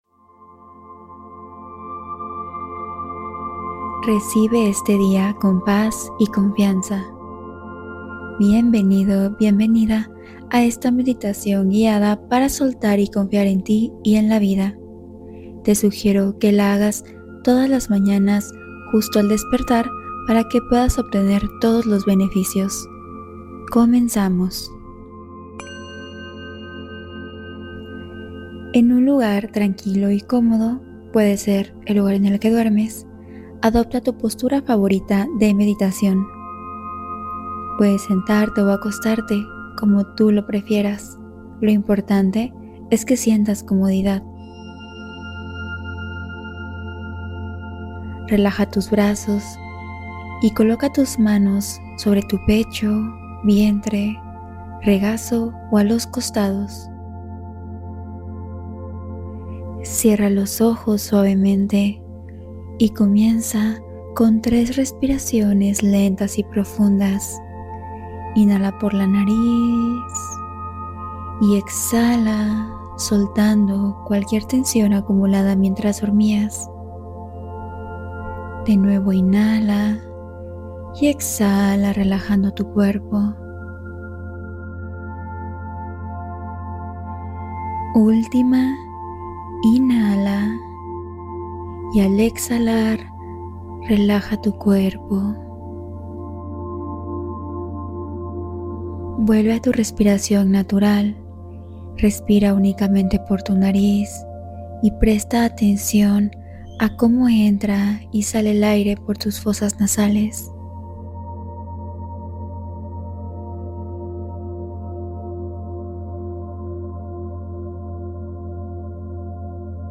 Suelta y Confía: Meditación Matinal para Iniciar el Día en Paz ☀